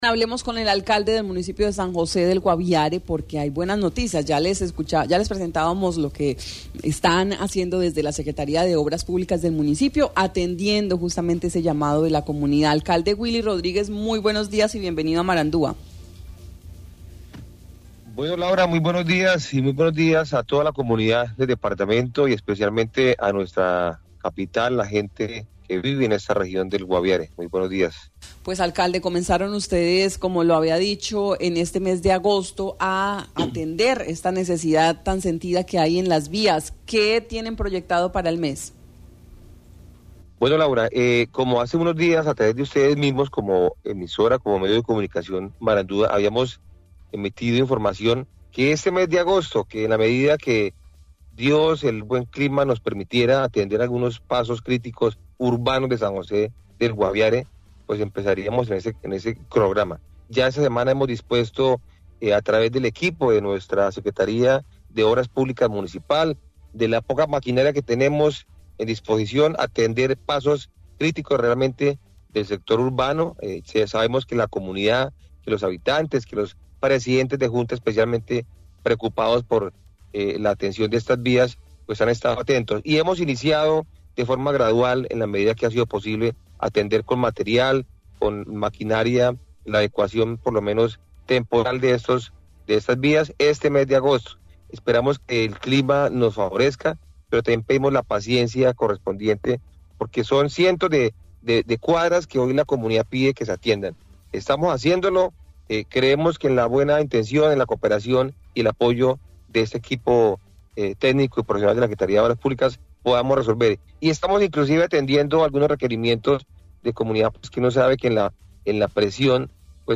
El alcalde de San José del Guaviare, Willy Rodríguez, habló de las proyecciones sobre las intervenciones de las vías de sectores urbanas y rurales,